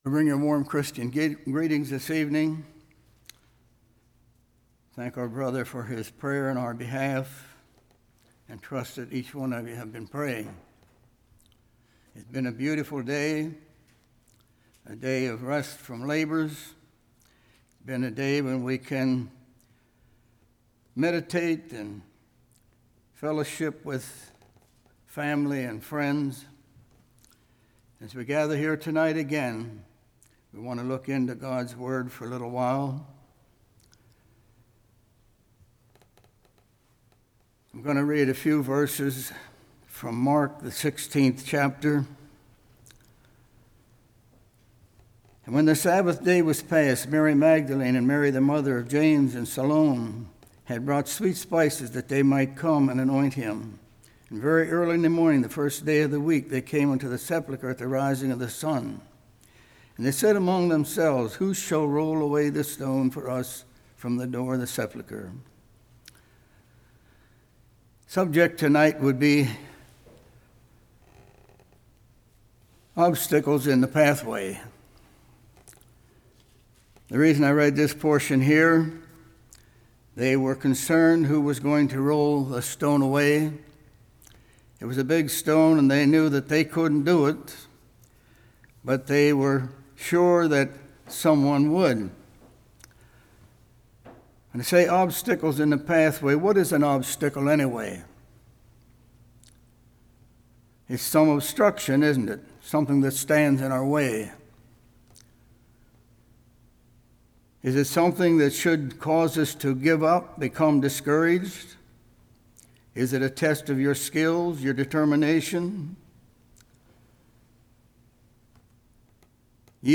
John 20:19-31 Service Type: Evening Self confidence Doubt Fear « What are Your Reactions?